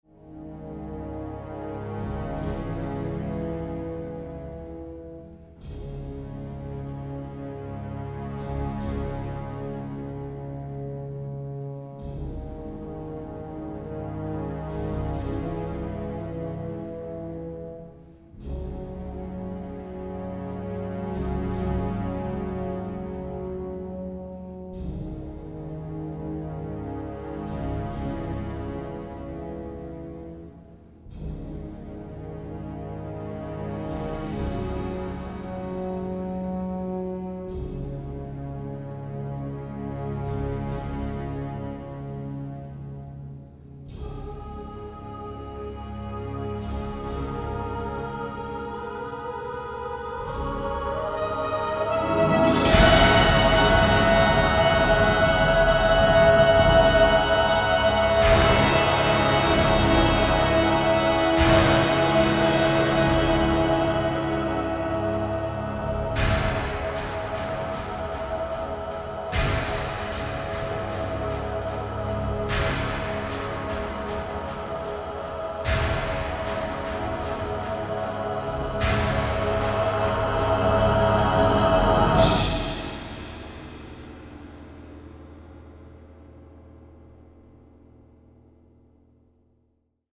contemporary electronics